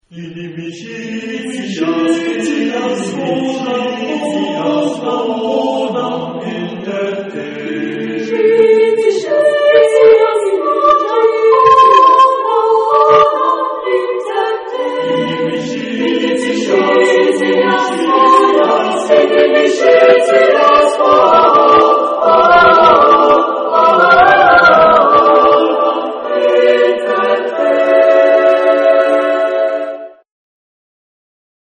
Genre-Style-Form: Sacred ; Motet
Mood of the piece: rhythmic ; mysterious
Type of Choir: SATB (div.)  (4 mixed voices )
Tonality: modal
Musicological Sources: Mixed octotonic modus